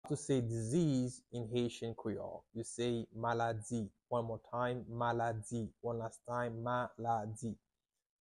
How to say "Disease" in Haitian Creole - "Maladi" pronunciation by a native Haitian teacher
“Maladi” Pronunciation in Haitian Creole by a native Haitian can be heard in the audio here or in the video below:
How-to-say-Disease-in-Haitian-Creole-Maladi-pronunciation-by-a-native-Haitian-teacher.mp3